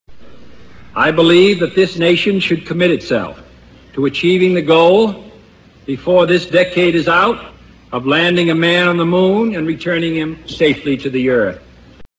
Kennedy’s "Moon speech" – Sweden took its first modest step into space.
kennedy.mp3